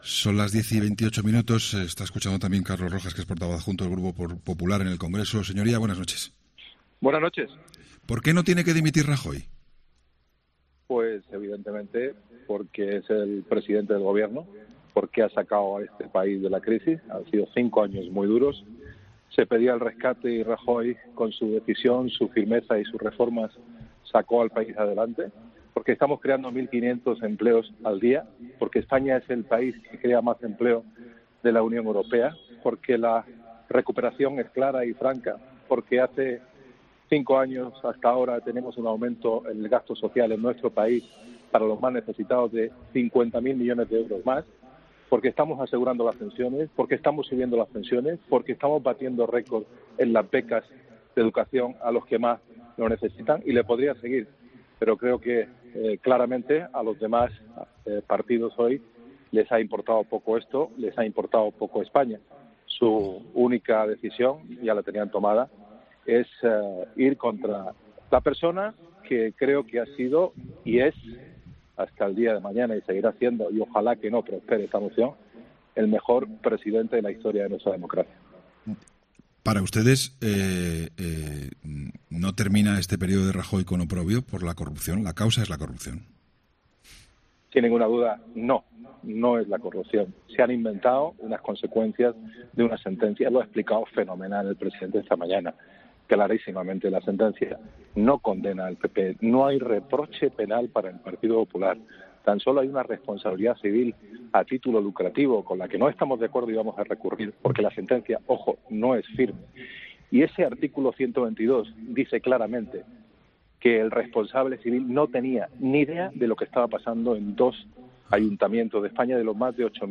Entrevistas en La Linterna
Carlos Rojas, portavoz adjunto del PP en el Congreso de los Diputados, ha atendido a 'La Linterna' minutos después de la primera jornada del debate de la moción de censura de Pedro Sánchez